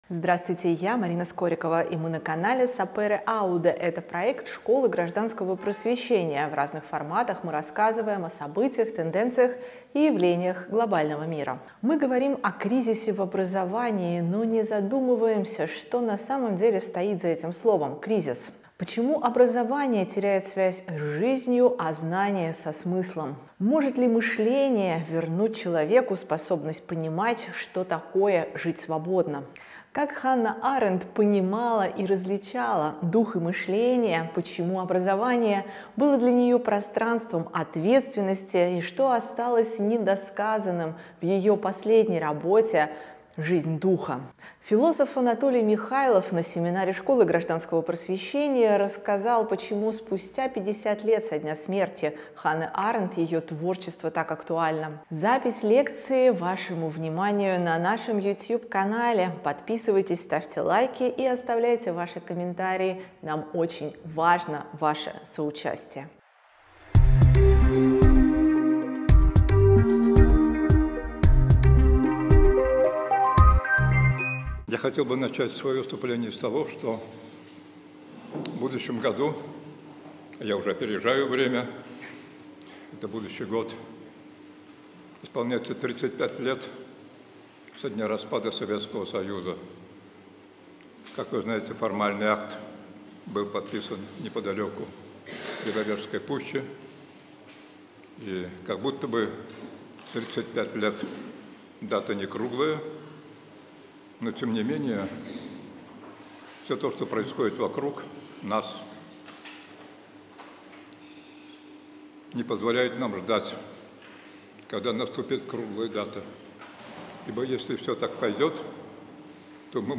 Запись выступления на семинаре Школы осенью 2025.